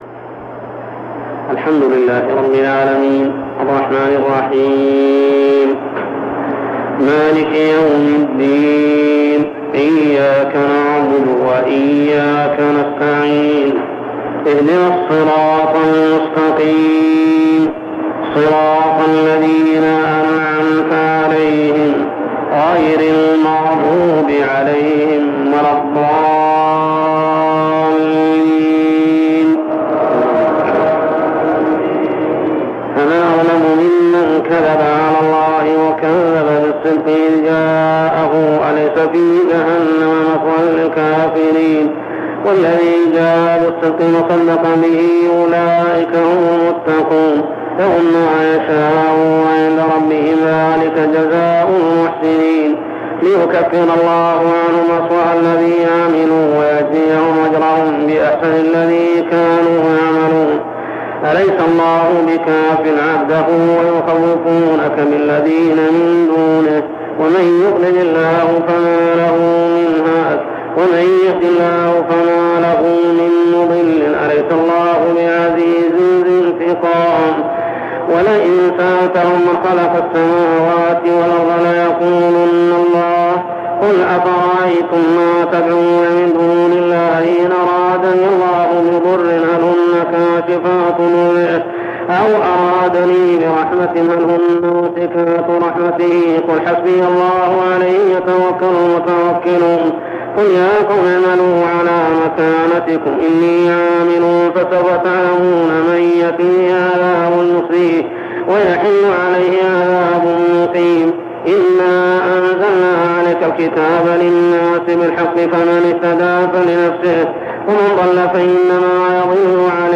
صلاة التراويح عام 1402هـ سورتي الزمر 32-75 ( الآيات 56-59 و 72-75 مفقودة ) و غافر 1-40 | Tarawih prayer Surah Az-Zumar and Ghafir > تراويح الحرم المكي عام 1402 🕋 > التراويح - تلاوات الحرمين